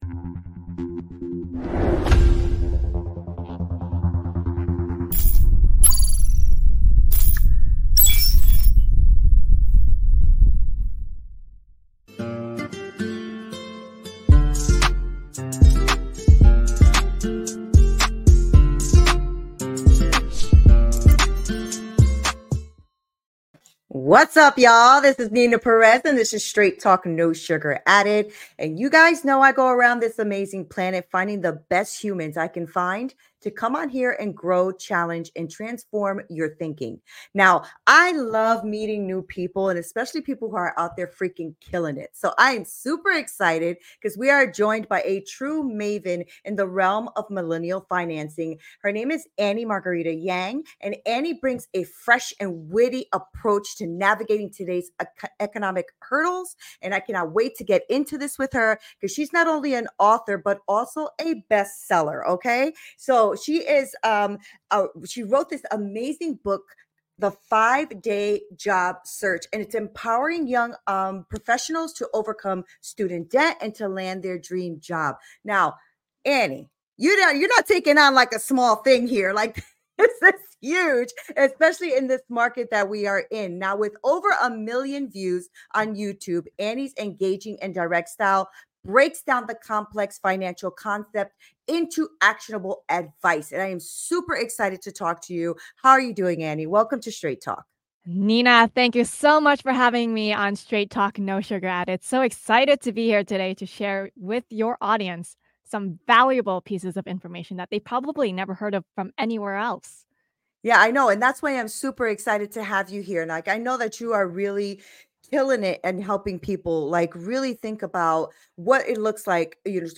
Introduction to Interview